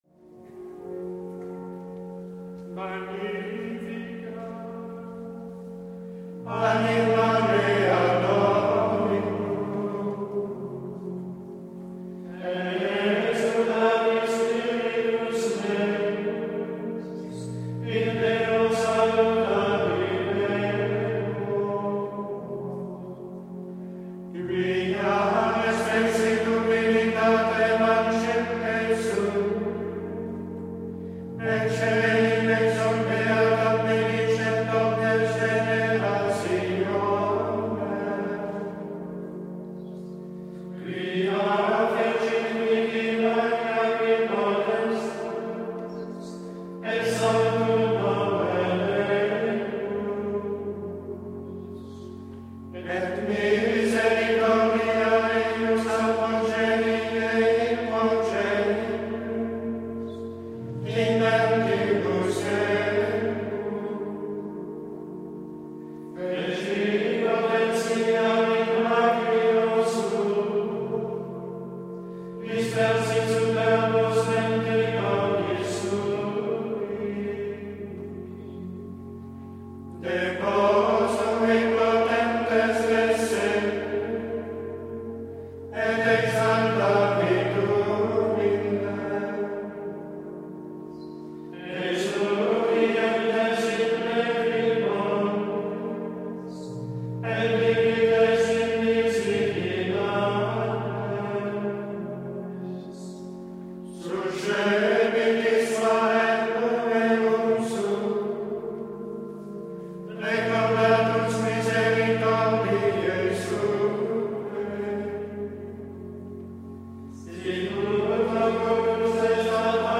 Γρηγοριανό μέλος [1]
[4] Tο ποιά είναι αυτή σε κάθε τρόπο γίνεται πολύ φανερό όταν υπάρχει ψαλμωδία, δηλαδή απαγγελία ενός κειμένου πάνω σε μιά επαναλαμβανόμενη νότα (με ένα εισαγωγικό και ένα καταληκτικό σχήμα), όπως για παράδειγμα στο Magnificat (τονική το φα):